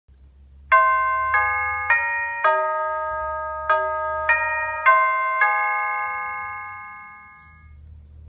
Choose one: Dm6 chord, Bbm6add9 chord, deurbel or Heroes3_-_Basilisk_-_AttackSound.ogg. deurbel